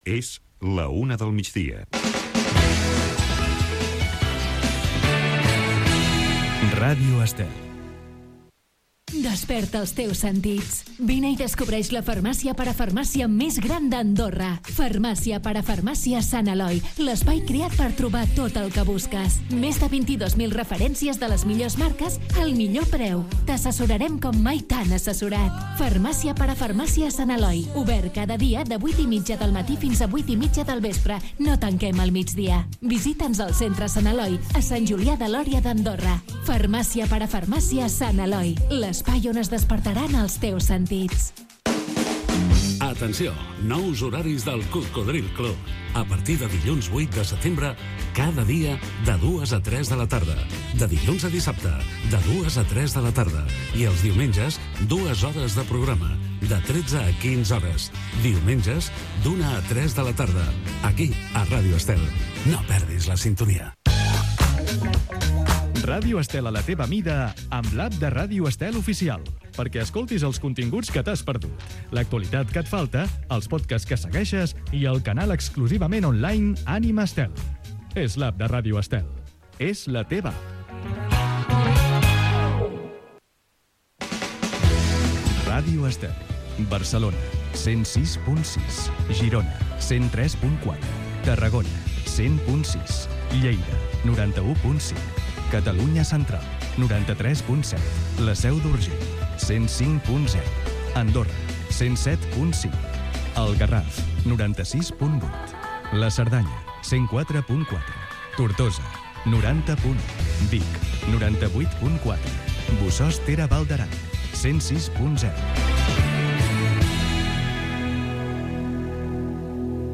Hora, indicatiu de la ràdio, publicitat, nous horaris del programa Cocodril Club, l'app de Ràdio Estel, indicatiu i freqüències de l'emissora. Careta del programa, presentació, la pel·lícula "Radio encubierta", tema musical
Musical
FM